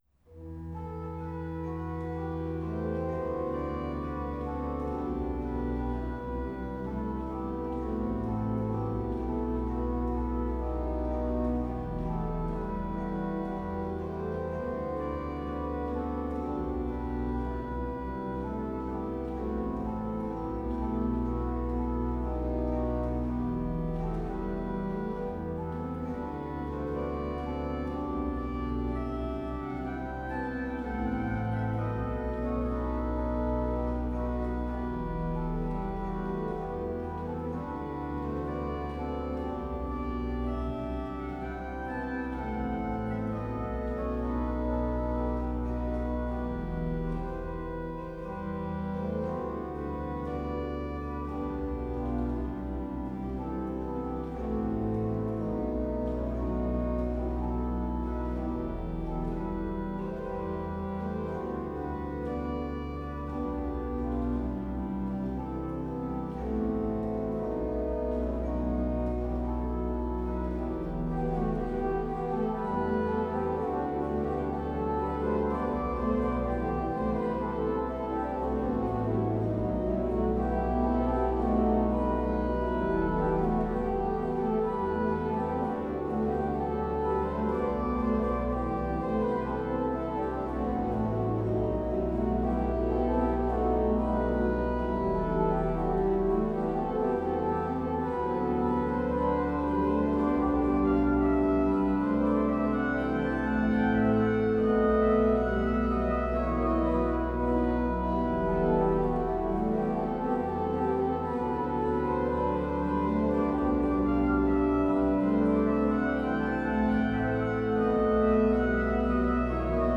Audio/Video - Organo Basilica Cattedrale di Fossano
Registrizioni amatoriali realizzate con Zoom H5N con capsula Zoom Msh-6 e/o microfoni esterni stereo AKG SE300B
Brani periodo Barocco